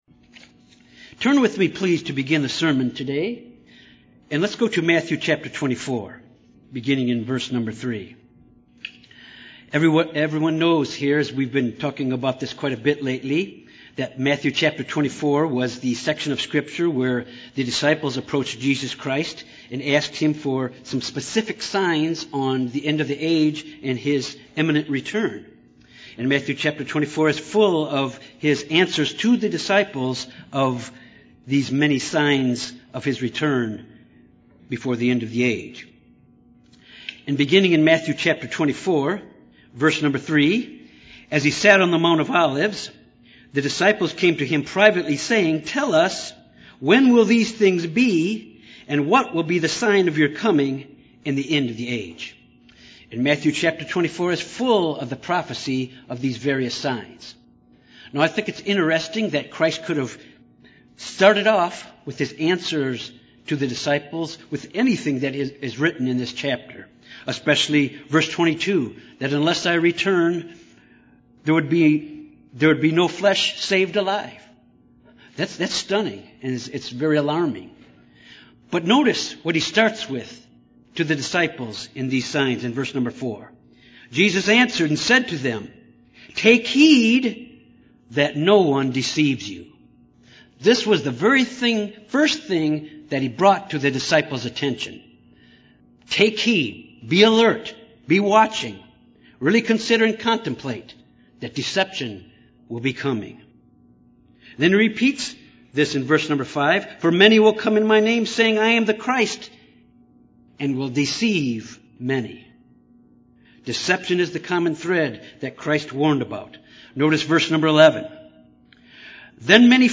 Given in Little Rock, AR Memphis, TN
UCG Sermon Studying the bible?